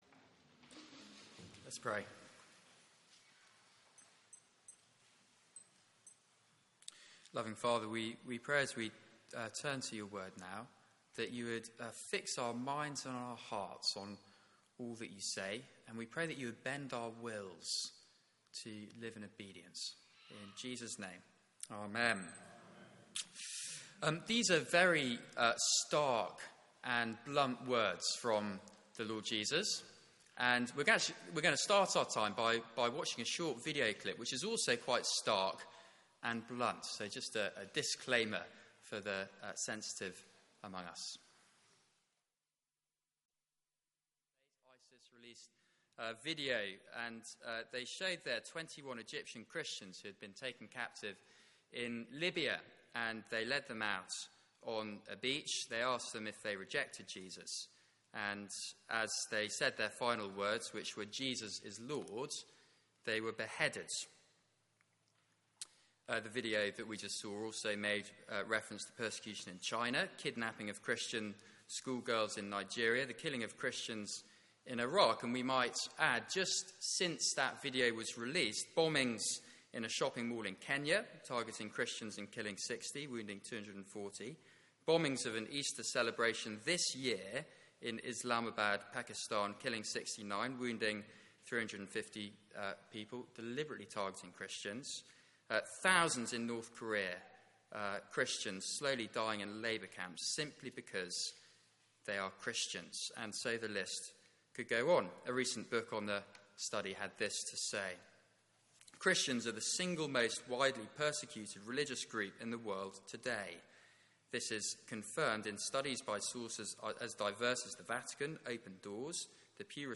Media for 9:15am Service on Sun 19th Jun 2016
Hated but chosen Sermon